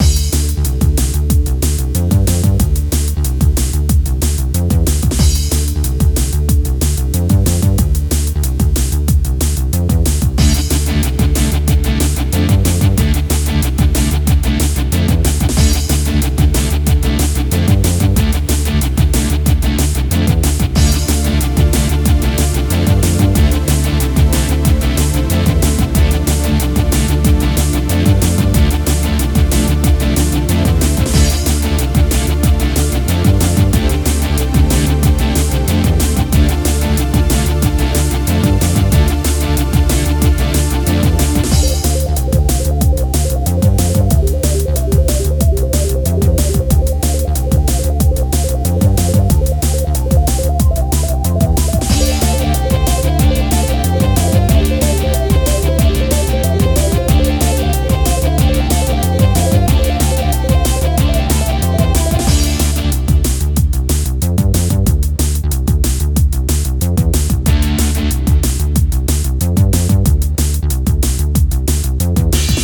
これから狩りや戦闘が始まりそうなイメージのBGM素材です。メロディーがないのであまり主張しない戦闘曲に。...